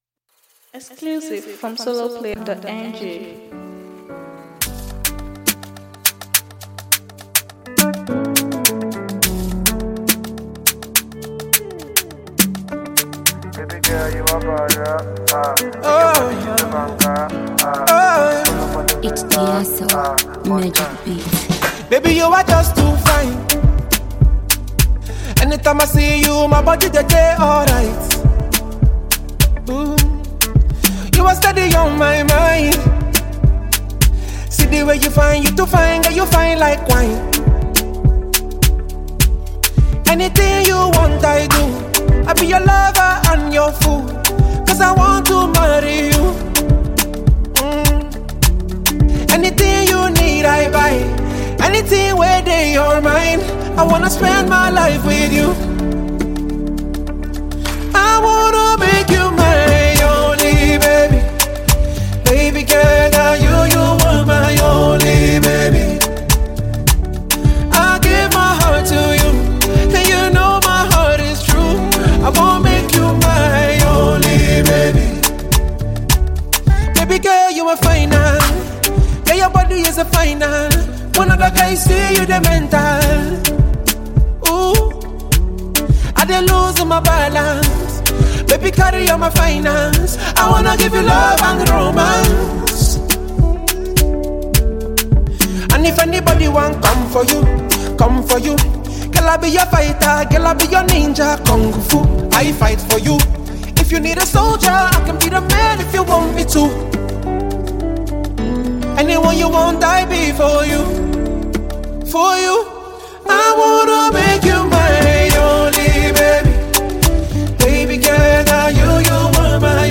radio and club-friendly Jam